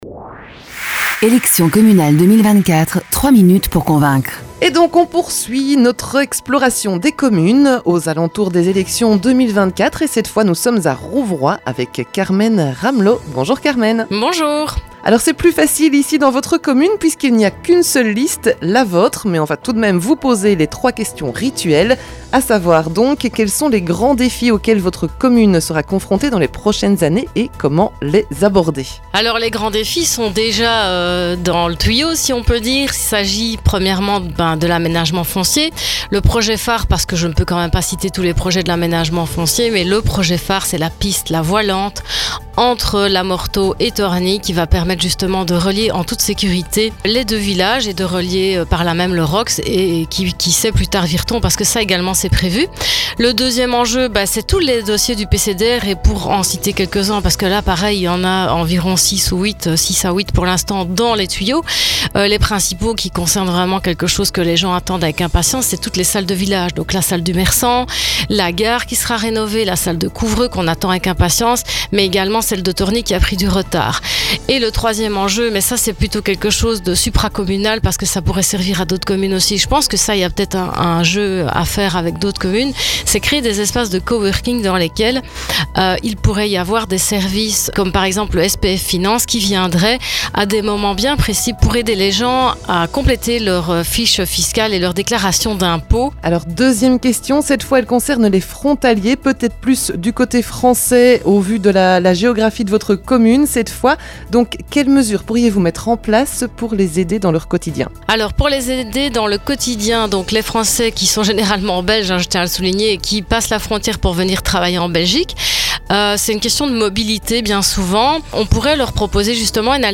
Ce jeudi, c'est Carmen Ramlot qui s'est prêté au jeu des questions-réponses avant les élections communales d'octobre.